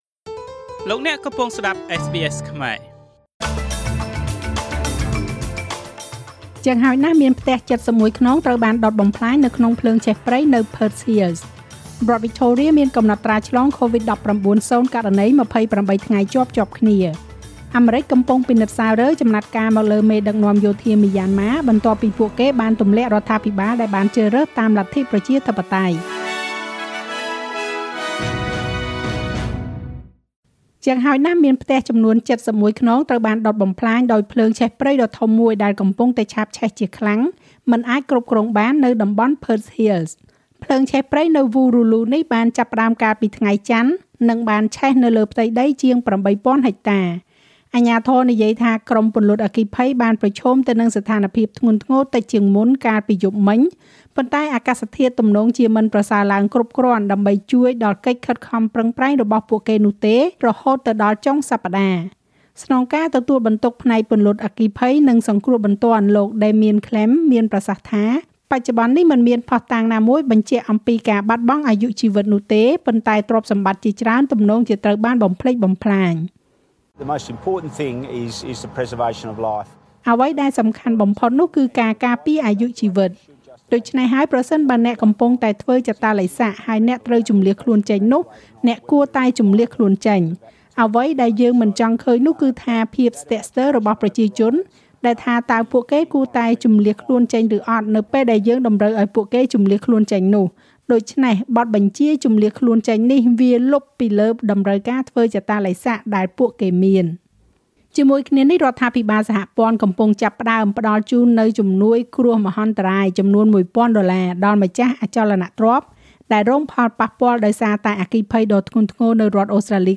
នាទីព័ត៌មានរបស់SBSខ្មែរ សម្រាប់ថ្ងៃពុធ ទី៣ ខែកុម្ភៈ ឆ្នាំ២០២១។